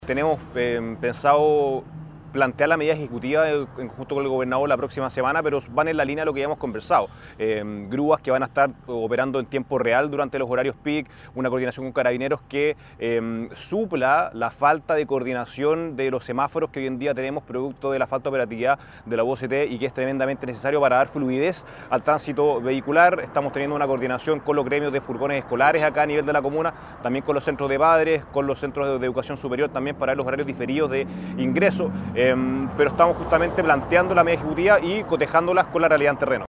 Durante la actividad, Spoerer también habló sobre la materialización de este proyecto.